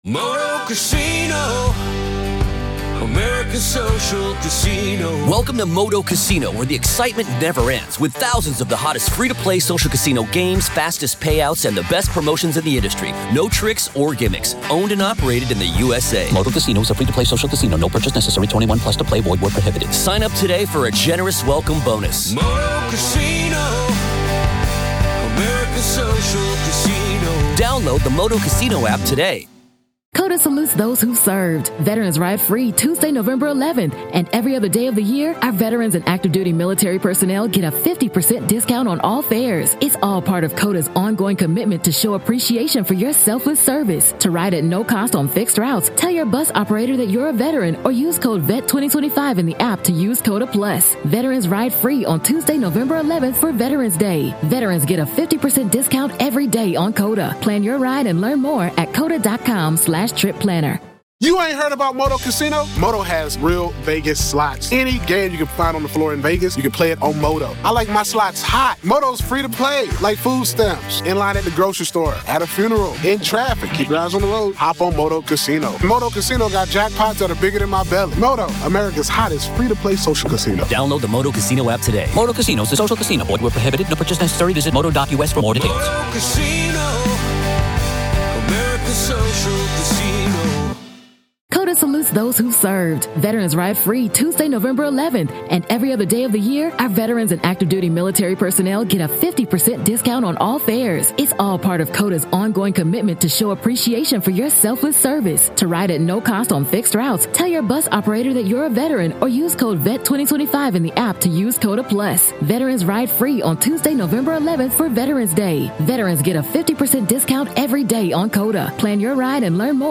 Raw Courtroom Audio